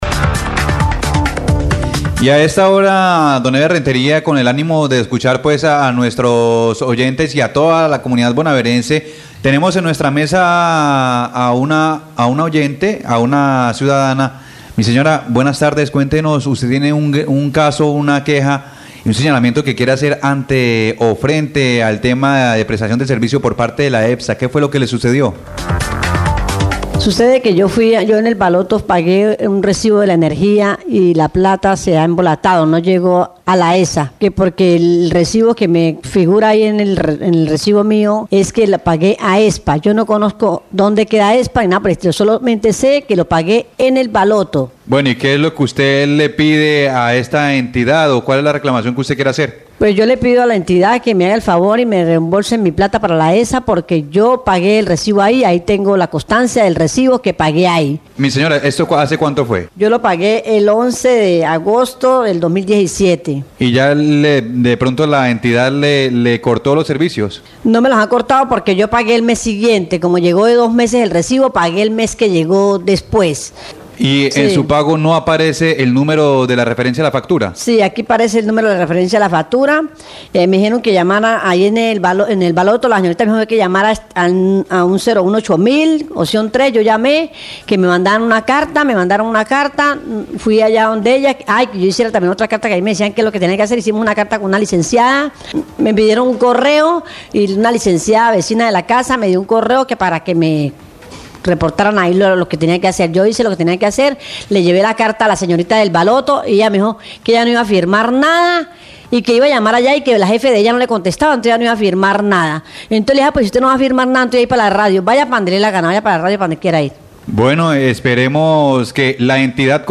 Radio
queja oyente
Pide que se le desembolse el dinero. Locutor aclara que la EPSA no tiene responsabilidad, que la responsabilidad es del BALOTO, porque allí fue donde realizó el pago.